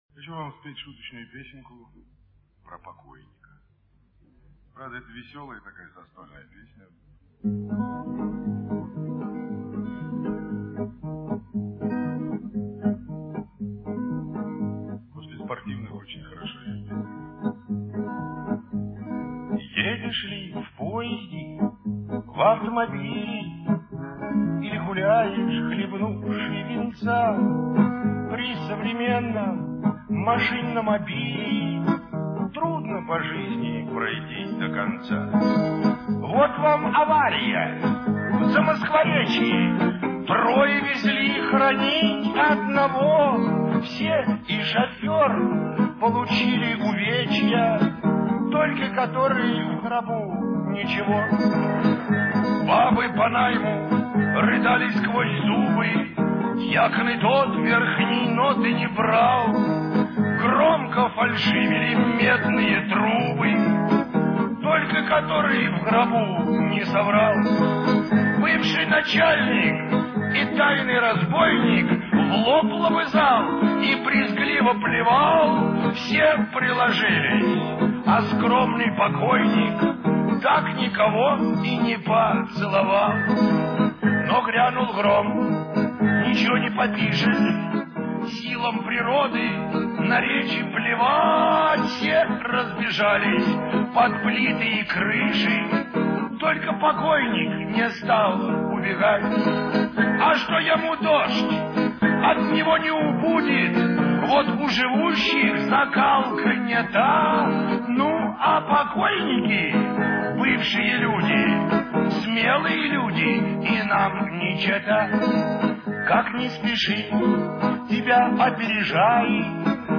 (с концерта)